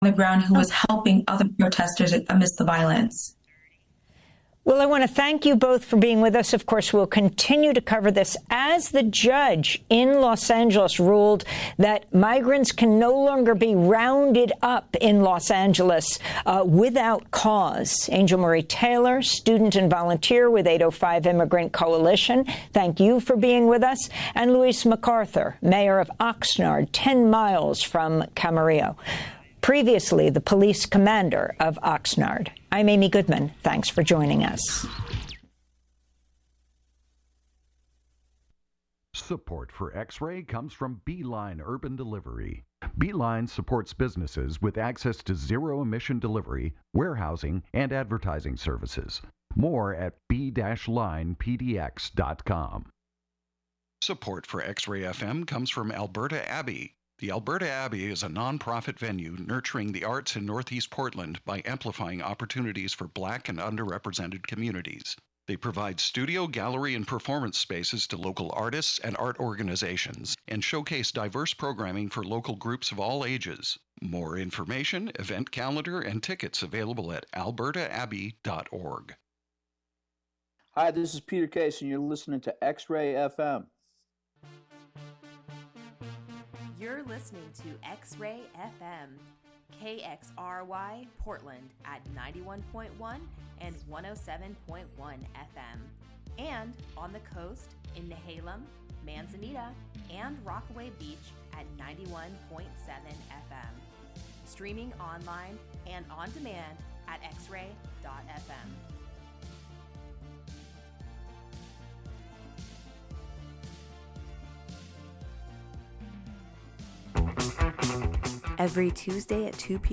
Listen in to hear my chat with Janet Weiss and Sam Coomes of Quasi, + hear about upcoming double header Lonely God Fest show at Swan Dive Aug 23rd, All Ages 4pm + Sea Moss, and an evening 8pm 21+...